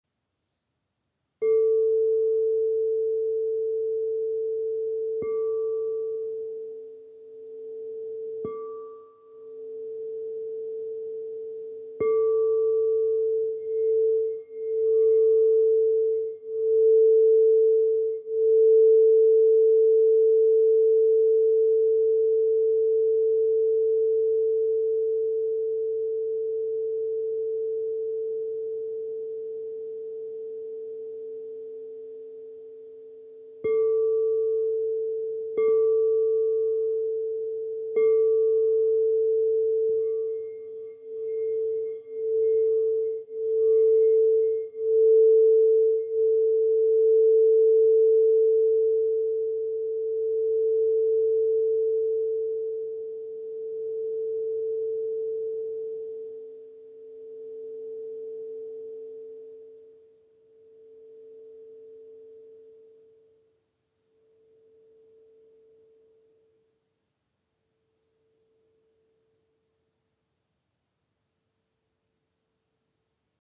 "Zpívající" Křišťálové mísy
Mísa tón E velikost 10" (25,5cm)
Ukázka mísa E
Mísa E-3.m4a